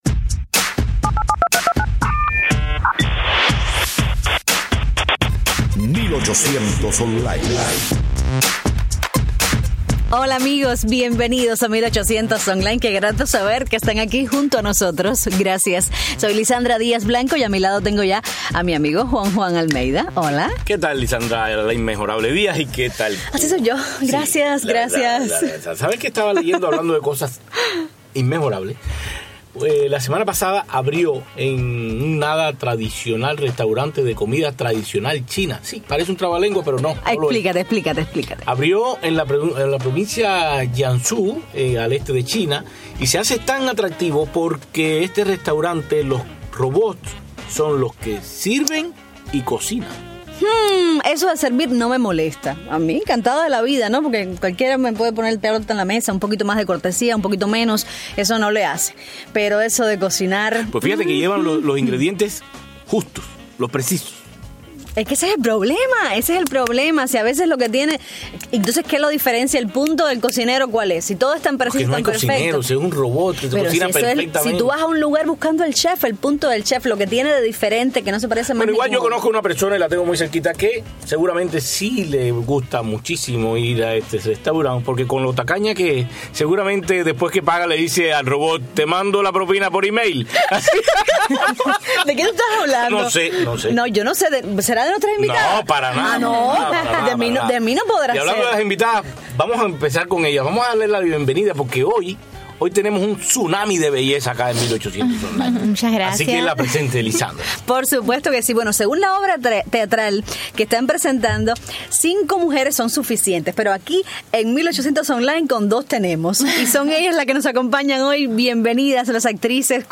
En 1800 Online, sigue la parada de artistas bellas! En vivo desde nuestros estudios